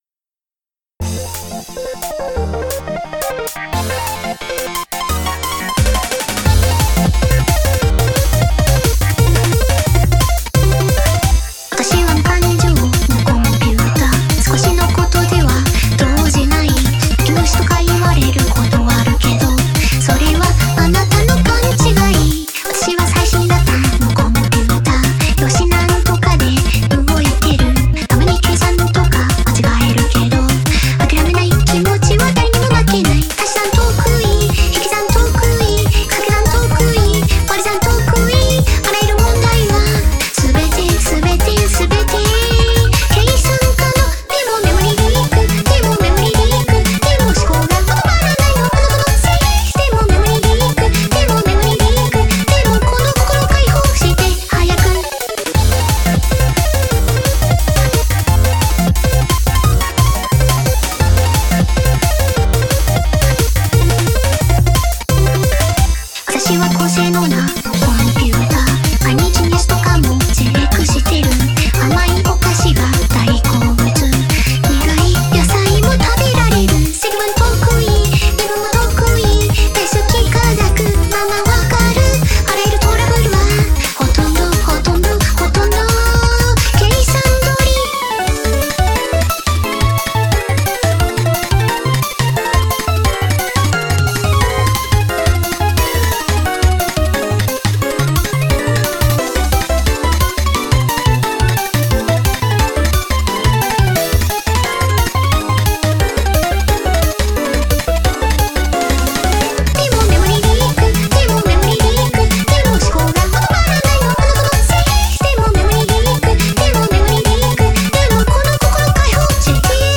MP3 with vocals